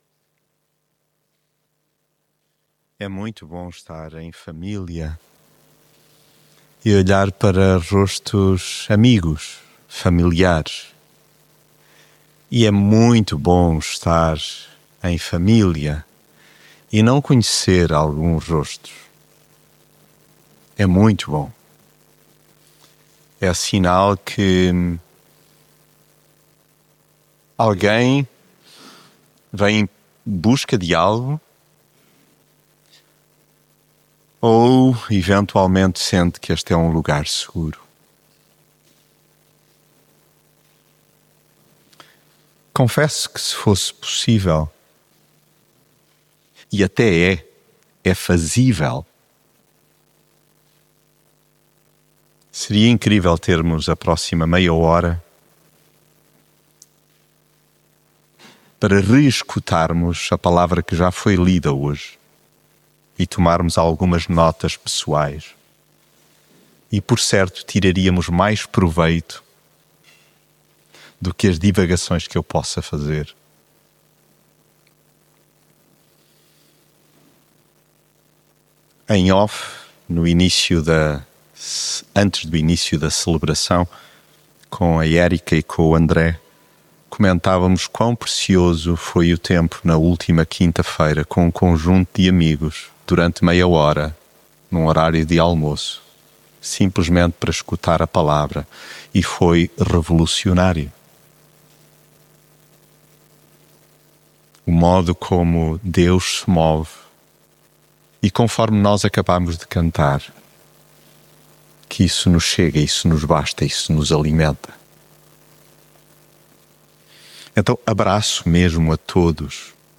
mensagem bíblica Arrepiar caminho, alterar o jeito de pensar e agir pode doer e custar muito, mas cura a alma, o ser inteiro.